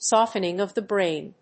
アクセントsóftening of the bráin